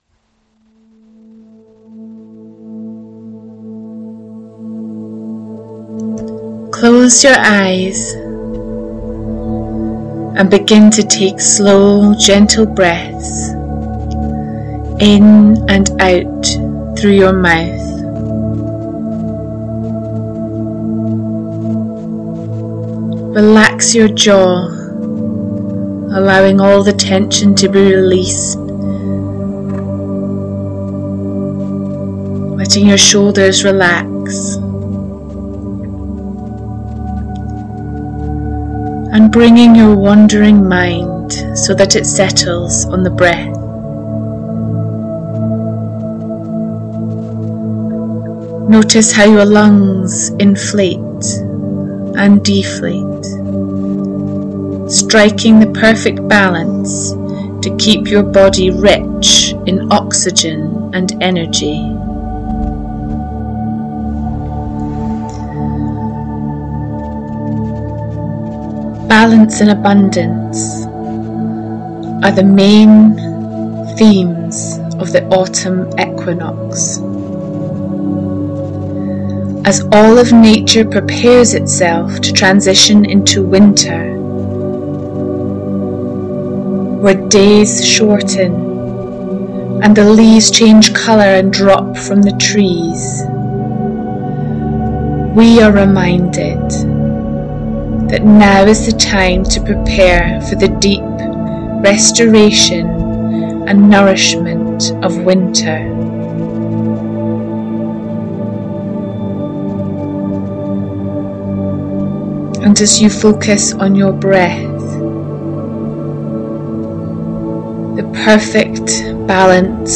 autumn-equinox-meditation.mp3